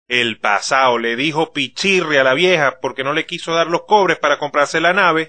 Az alábbiakban meghallgatható egy jelképes mondat néhány ország sajátos szlengjén.[3]
Venezuela venezuelai